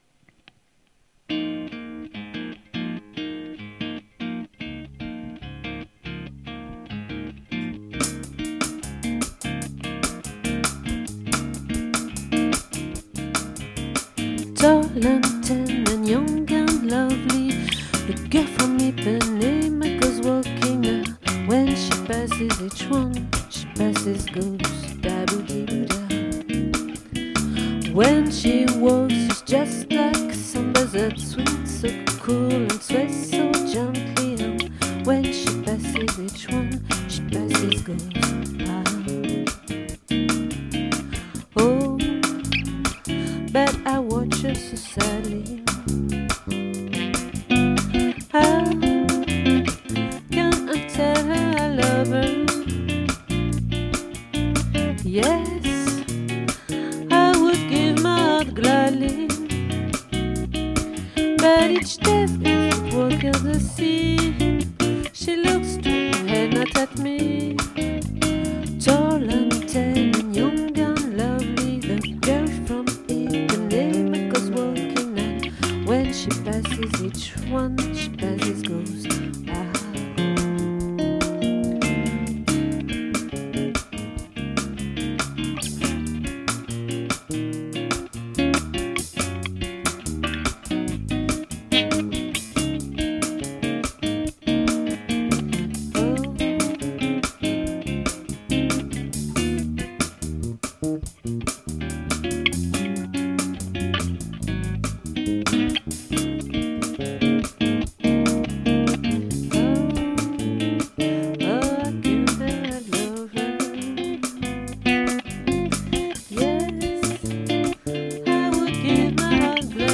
🏠 Accueil Repetitions Records_2022_02_02